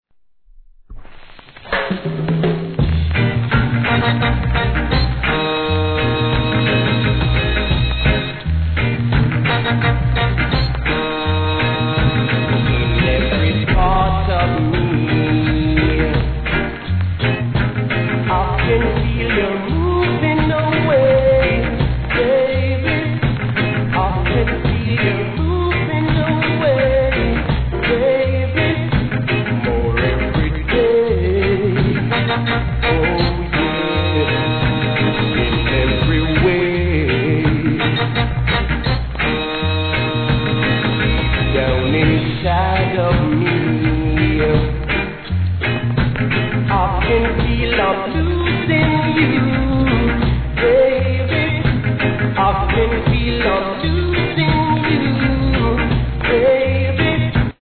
序盤にサー目立ちます
REGGAE